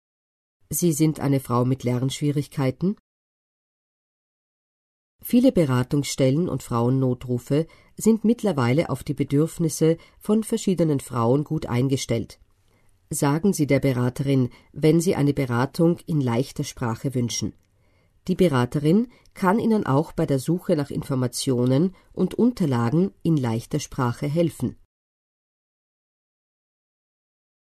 Hier finden Sie die österreichische Broschüre für Frauen mit Behinderungen als Audioversion: „Gewalt, was kann ich tun? Informationen für Frauen mit Behinderungen.“